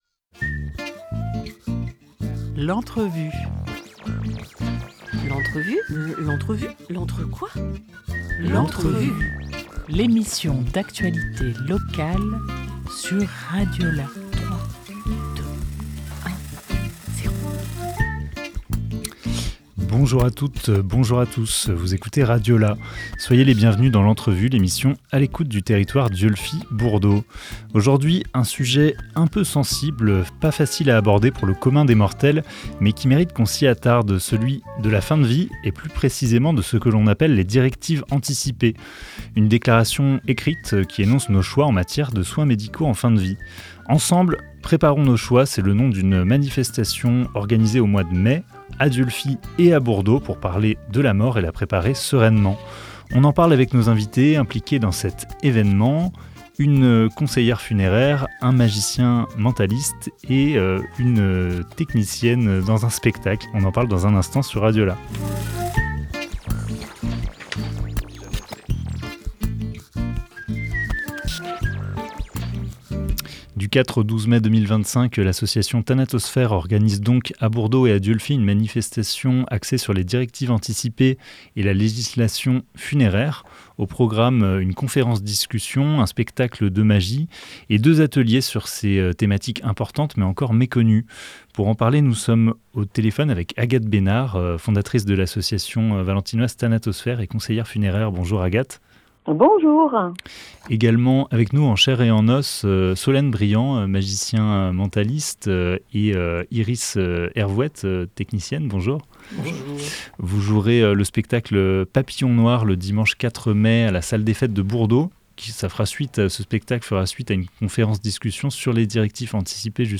22 avril 2025 11:11 | Interview